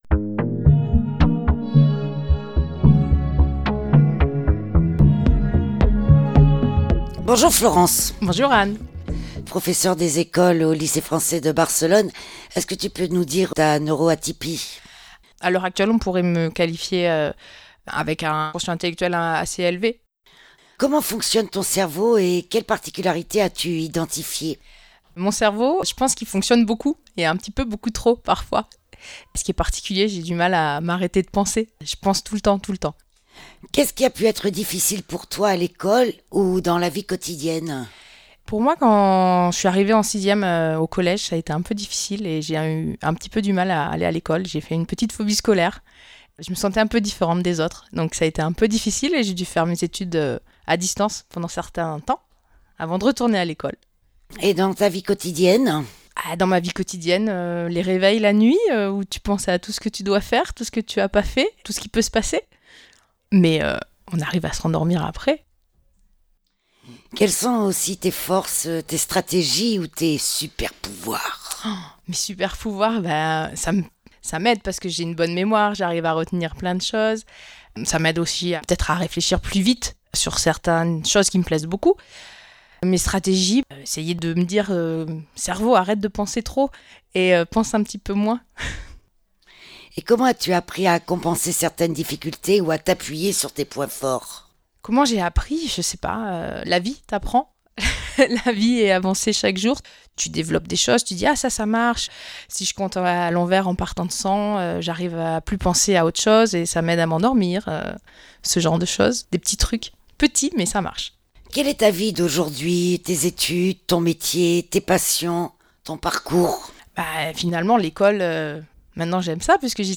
28/04/2026 à 16h 15| 3 mn | interview| primaire |